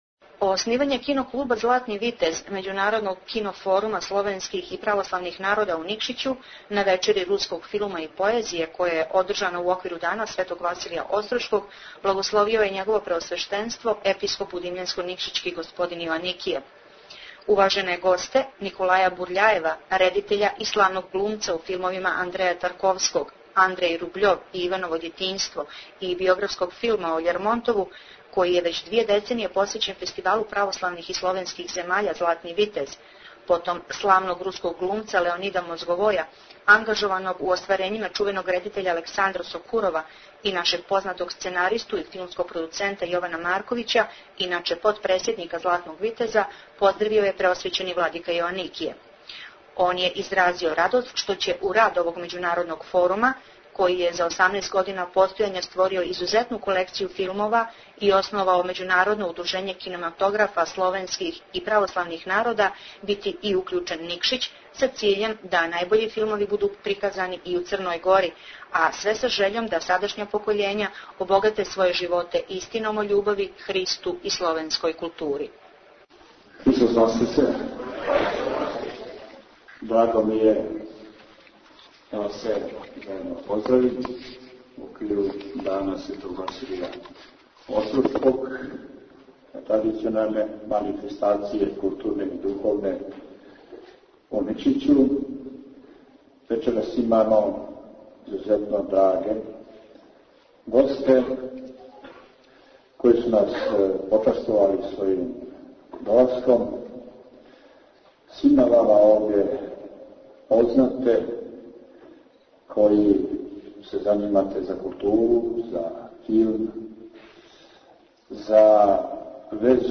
Извјештаји